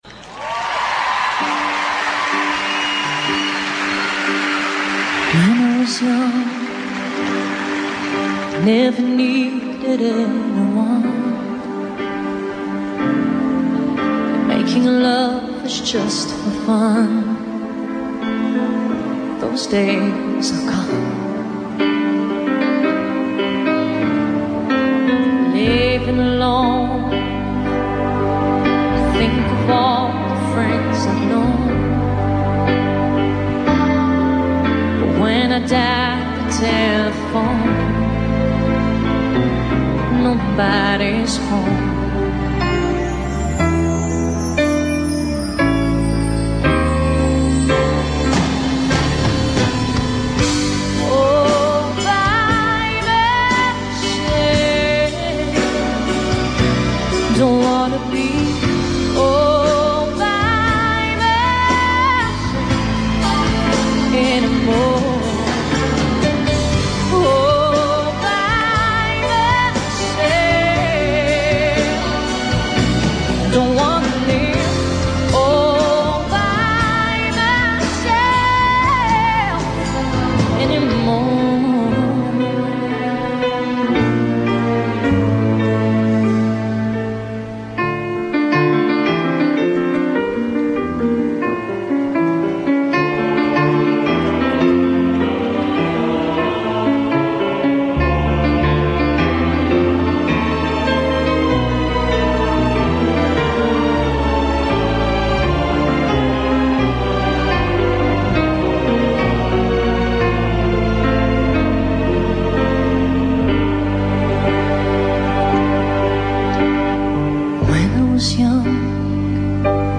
Téléchargez Autre Live de 1997, plus vibrant, plus émouvant.